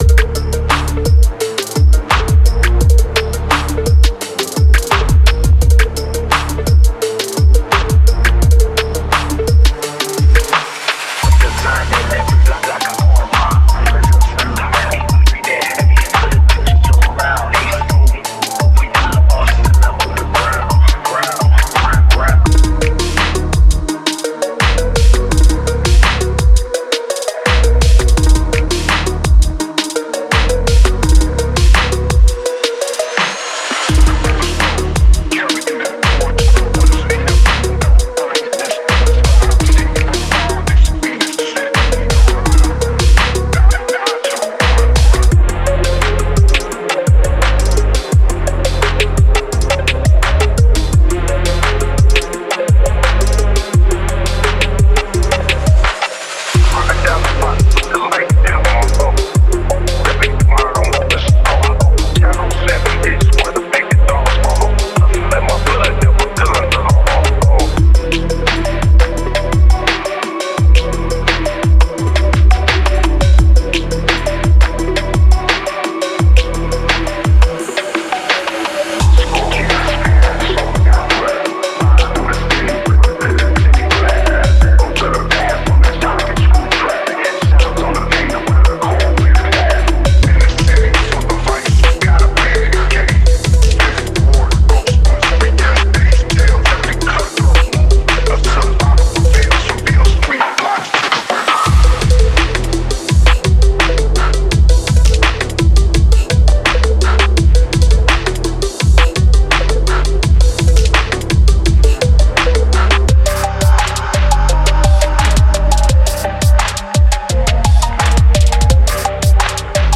Genre:Trap
デモサウンドはコチラ↓
41 Drum Part Loops
41 Melody Loops
20 Vocal Phrases